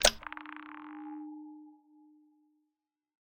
lock2.ogg